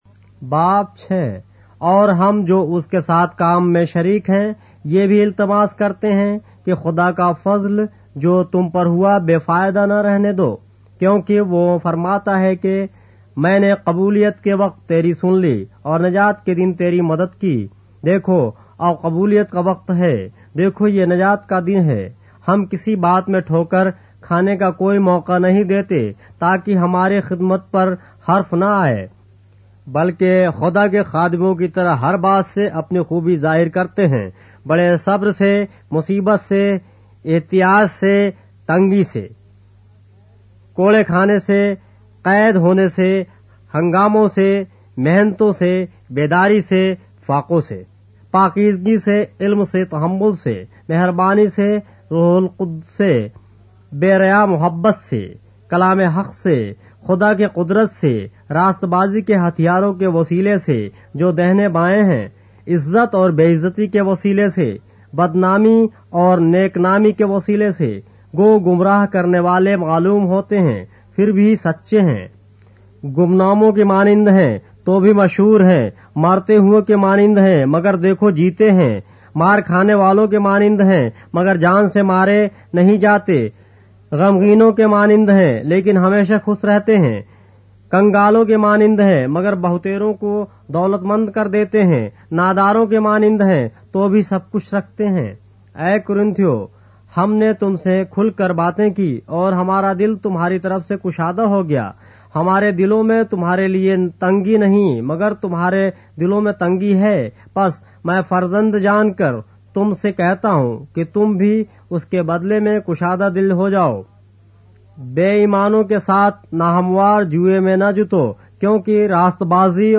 اردو بائبل کے باب - آڈیو روایت کے ساتھ - 2 Corinthians, chapter 6 of the Holy Bible in Urdu